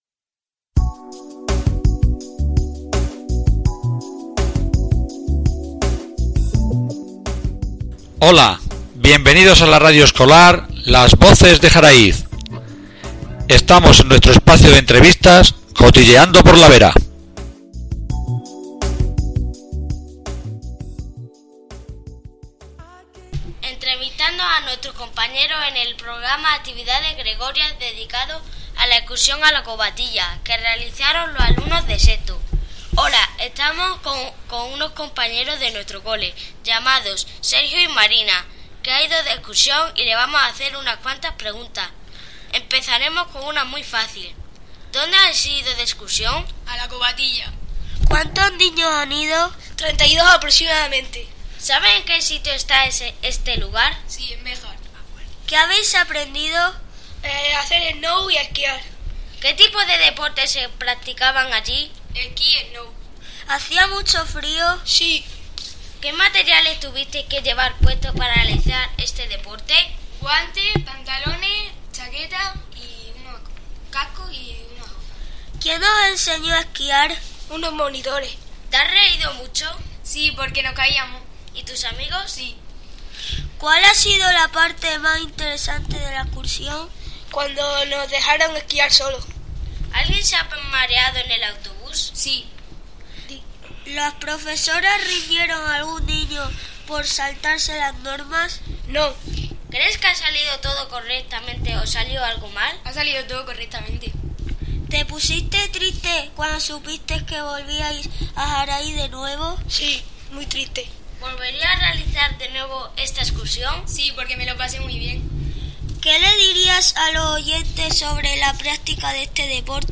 ENTREVISTA EXC. COVATILLA